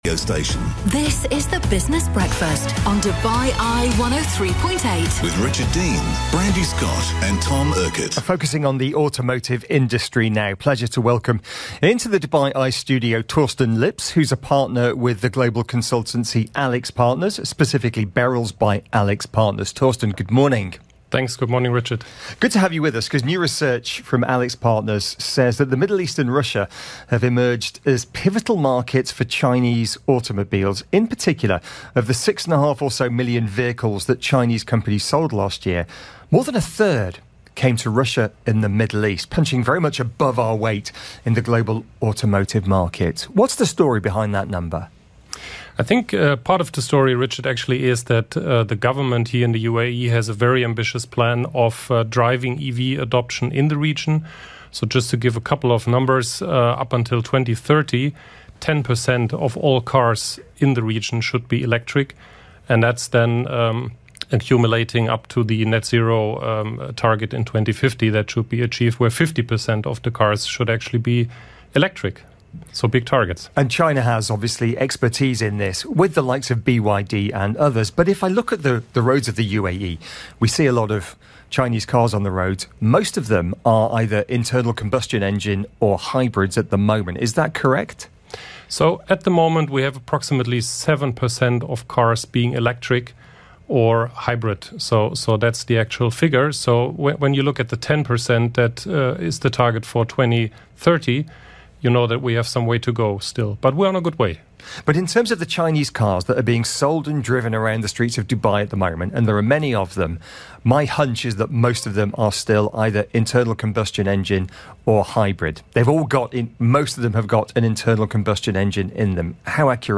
update from Dubai on the development of EVs in the Middle East.